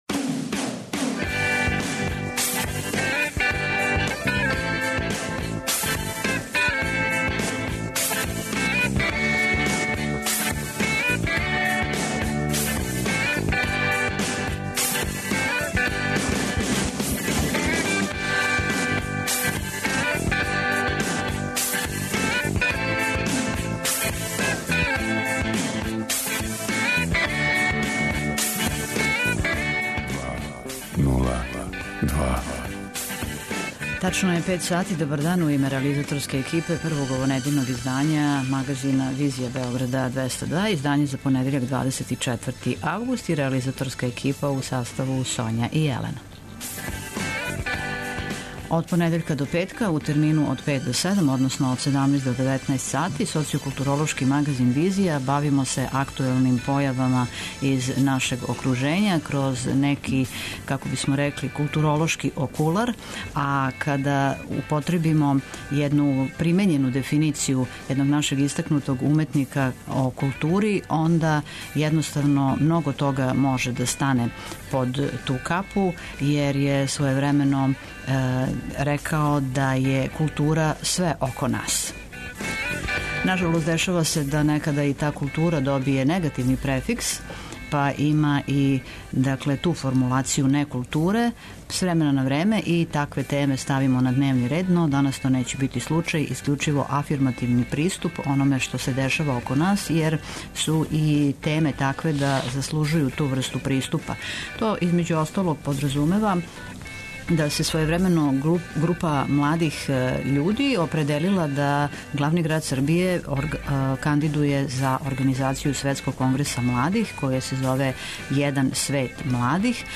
Социо-културолошки магазин, који прати савремене друштвене феномене.